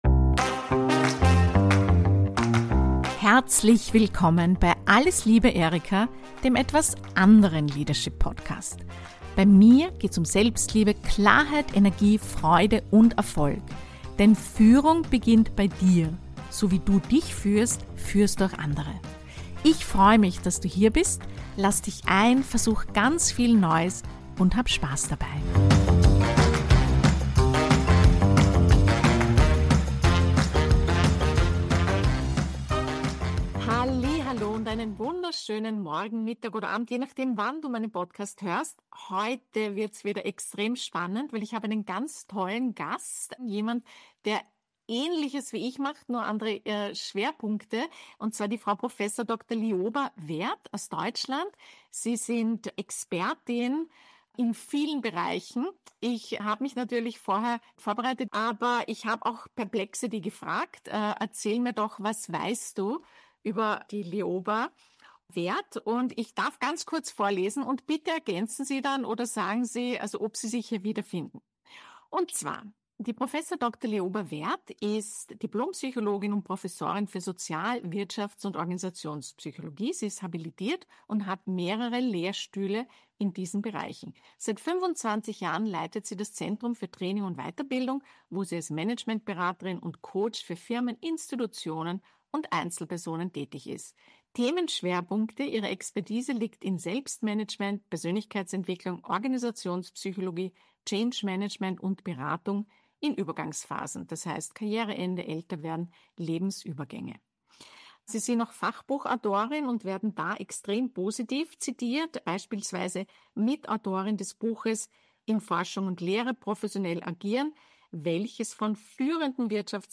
Abgerundet wird das Gespräch durch persönliche Erfahrungen, Humor und wertvolle Denkanstöße.